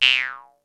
VARIOUS FILT 3.wav